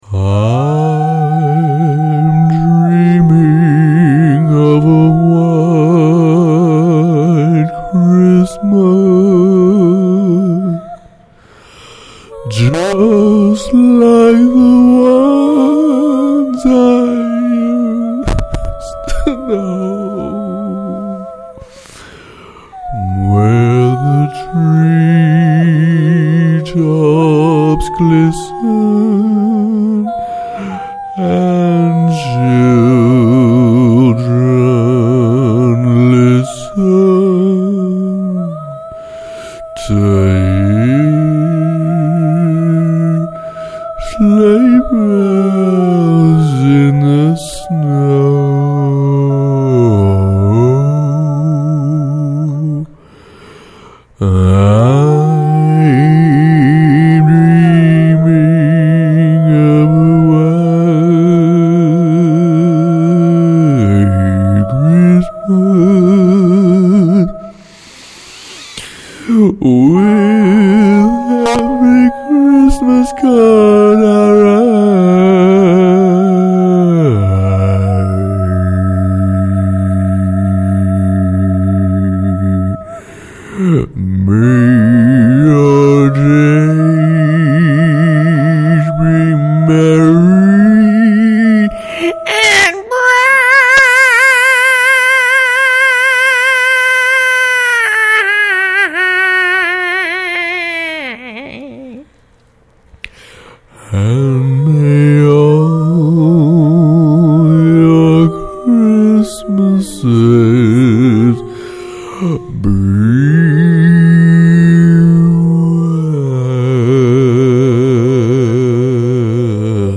Voices